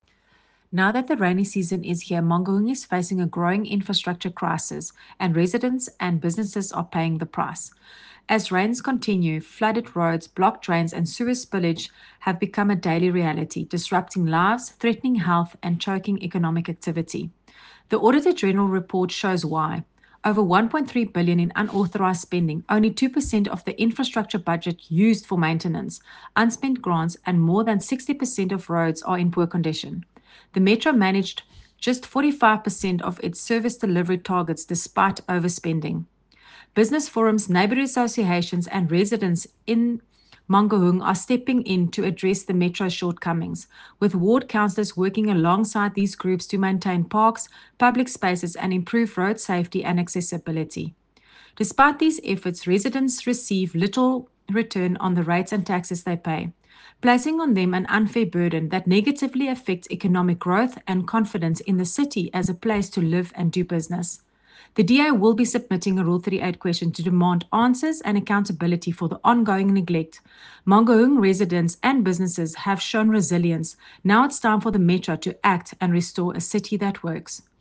Afrikaans soundbites by Cllr Corize van Rensburg and